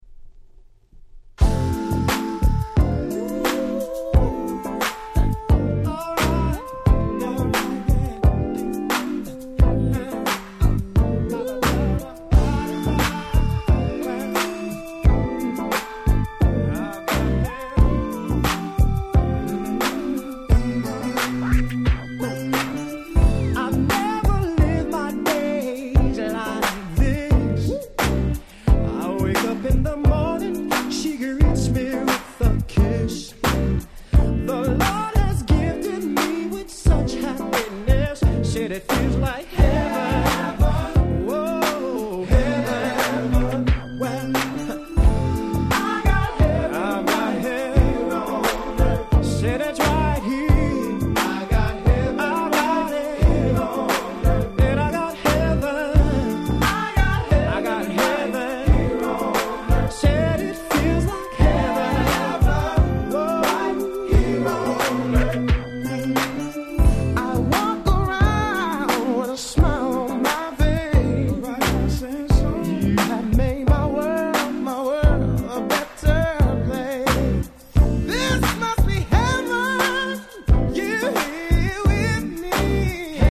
95' Nice R&B !!